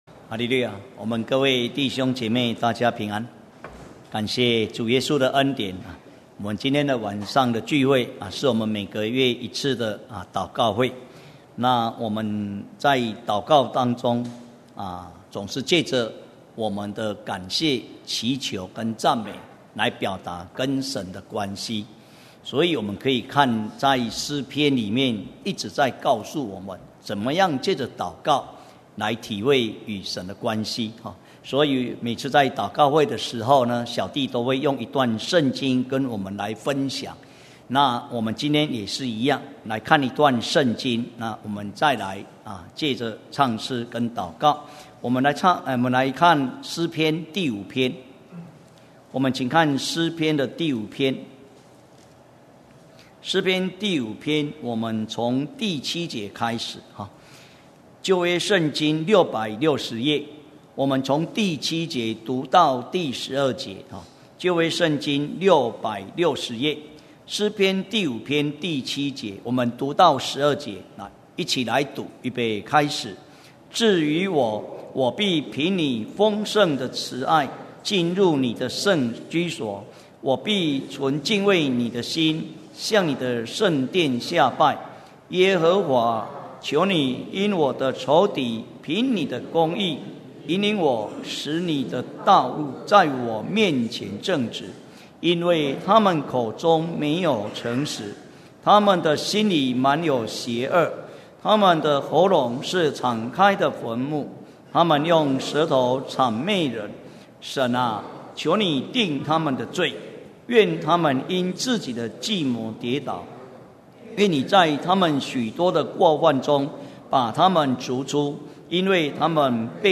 2017年6月份講道錄音已全部上線